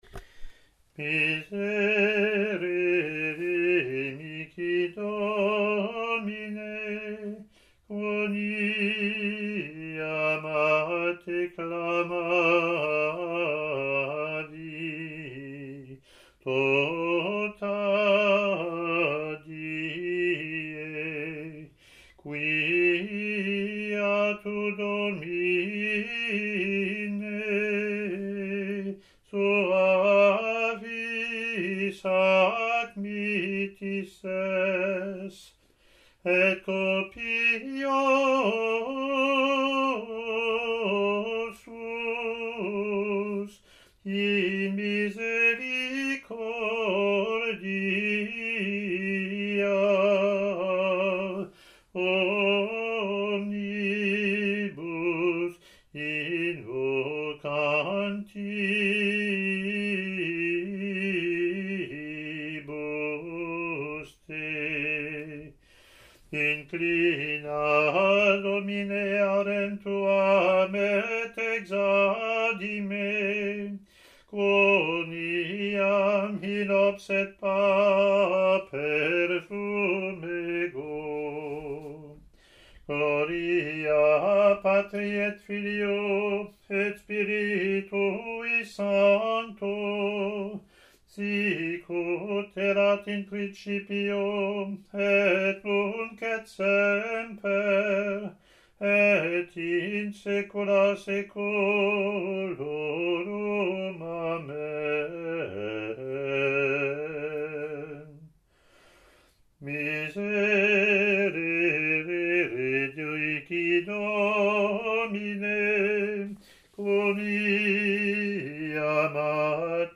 Roman Gradual – Ordinary Form of the Roman Rite
Latin antiphon and verse)